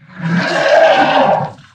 255081e1ee Divergent / mods / Soundscape Overhaul / gamedata / sounds / monsters / bloodsucker / attack_1.ogg 14 KiB (Stored with Git LFS) Raw History